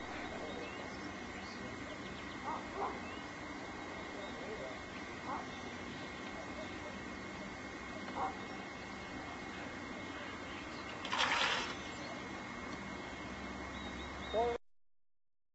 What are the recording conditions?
Electra landing RealVideo clip at Fulford airstrip (15.5 seconds, 161 K)